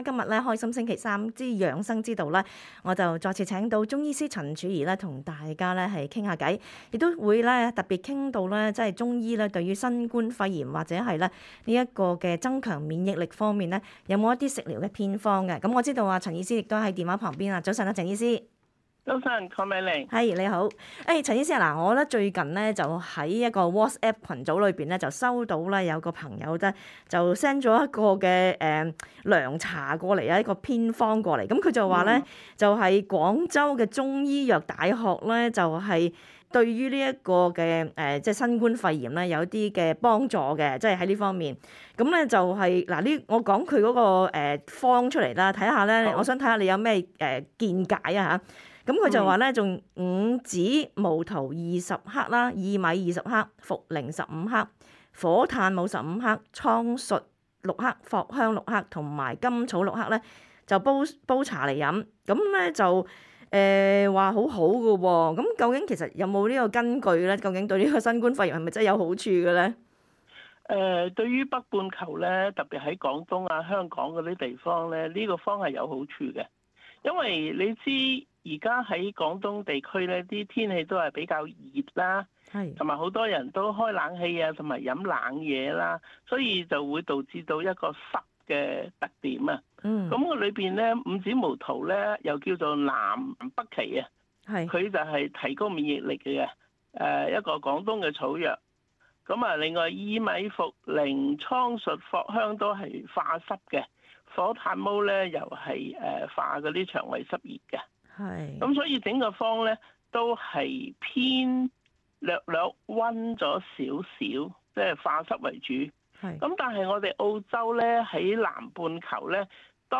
READ MORE 【另類隔離住宿】新州重災小鎮引入露營車隔離感染者 【取代酒店】昆州偏遠地區建隔離營 年底供應500床位 首都領地優先為12年級學生打輝瑞疫苗 確保考試順利 *本節目內嘉賓及聽眾意見並不代表本台立場 在澳洲，所有人必須保持至少 1.5 米的社交距離。